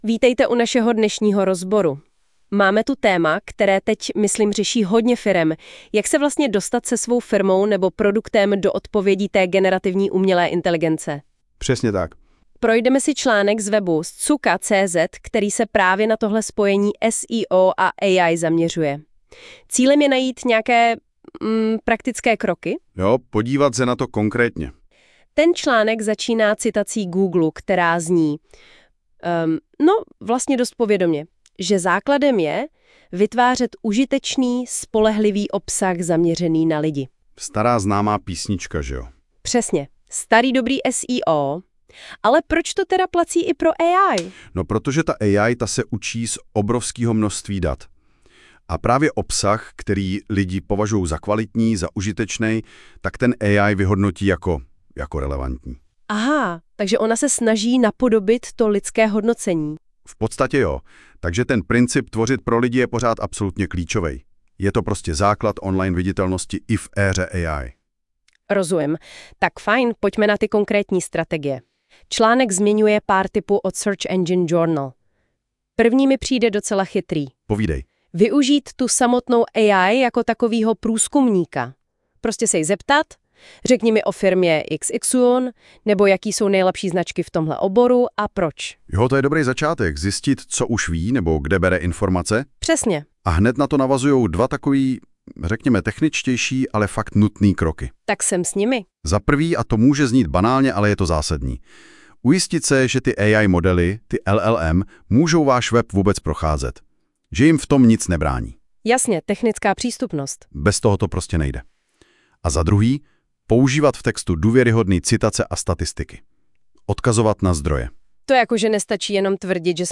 AI hovoří s AI o AI.